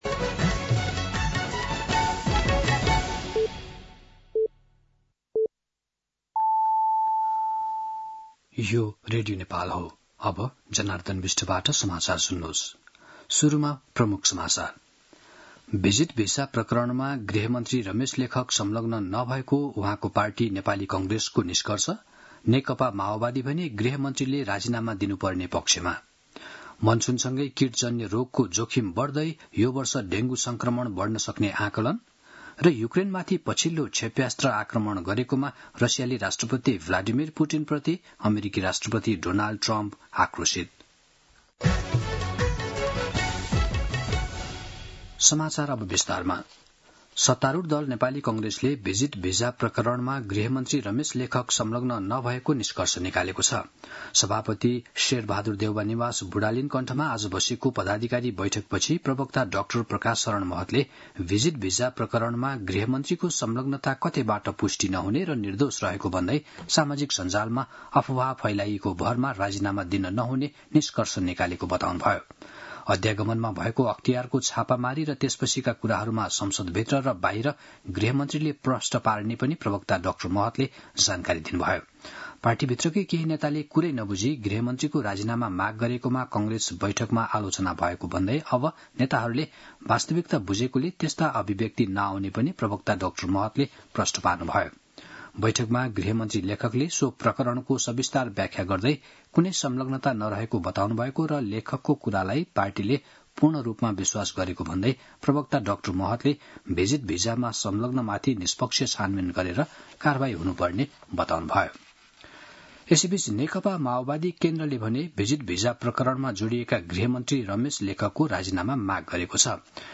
दिउँसो ३ बजेको नेपाली समाचार : १२ जेठ , २०८२
3-pm-News-12.mp3